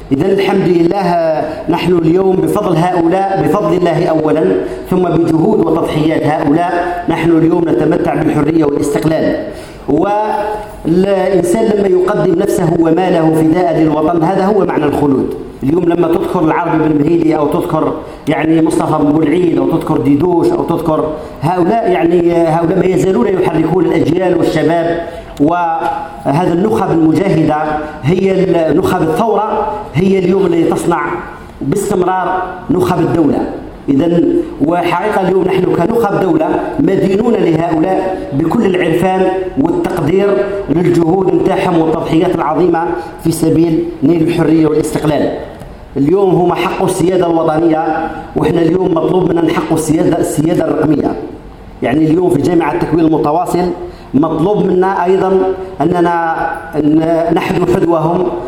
إحتفال جامعة التكوين المتواصل بالذكرى المزدوجة لعيدي الإستقلال و الشباب